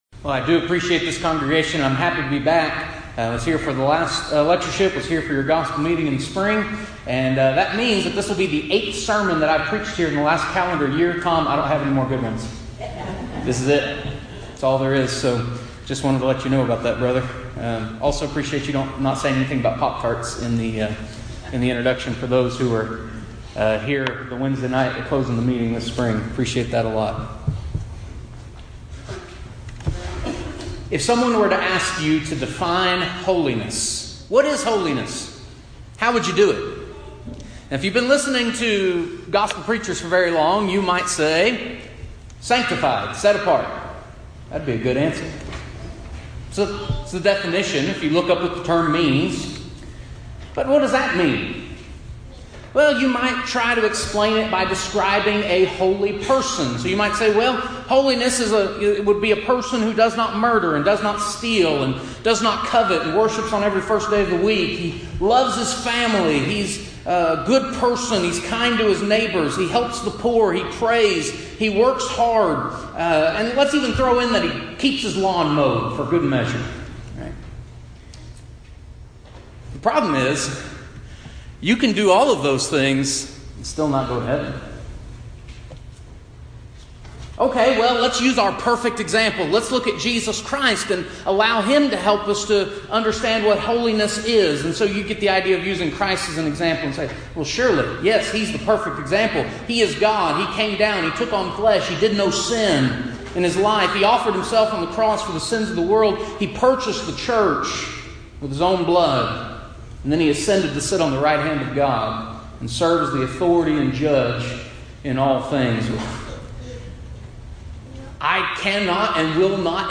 Event: 10th Annual Back to the Bible Lectures
If you would like to order audio or video copies of this lecture, please contact our office and reference asset: 2019BackToTheBible01